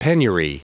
Prononciation du mot penury en anglais (fichier audio)
penury.wav